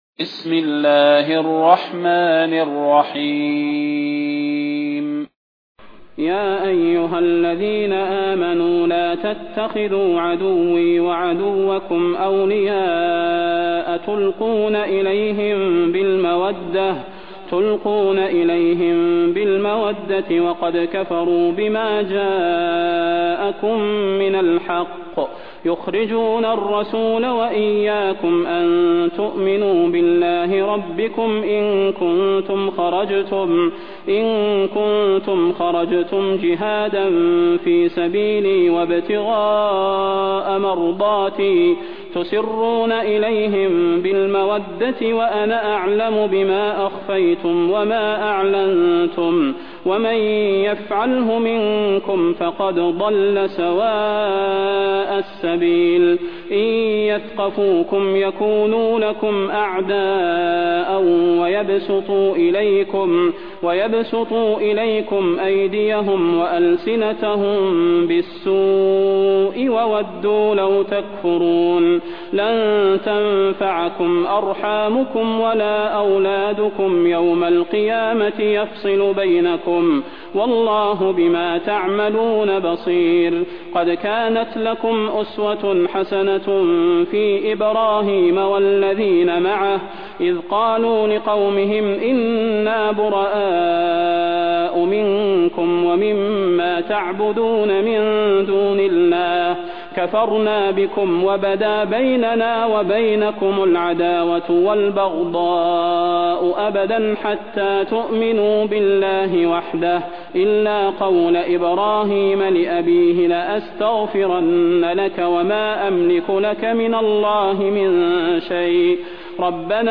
المكان: المسجد النبوي الشيخ: فضيلة الشيخ د. صلاح بن محمد البدير فضيلة الشيخ د. صلاح بن محمد البدير الممتحنة